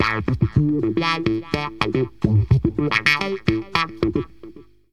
funkres.mp3